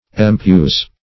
\Em*puse"\